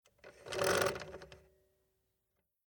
Kinder-Schreibmaschine Bambino
Schreibhebel bewegen
0035_Schreibhebel_bewegen.mp3